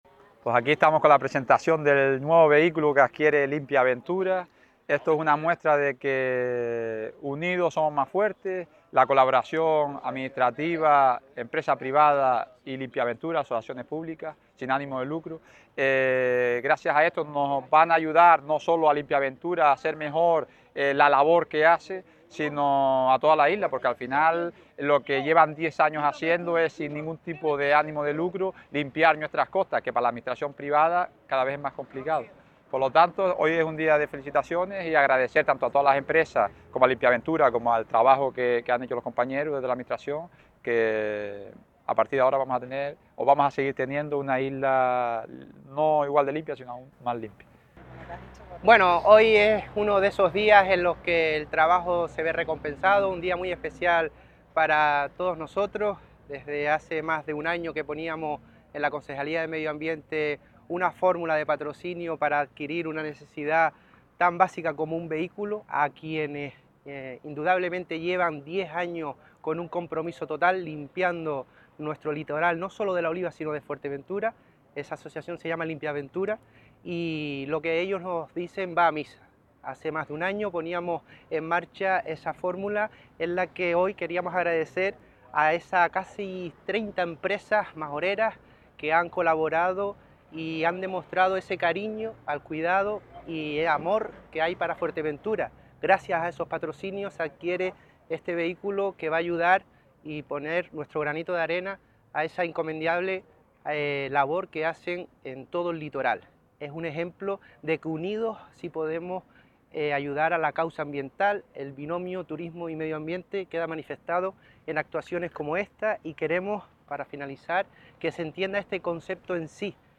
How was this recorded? Fuerteventura Digital | En Estudio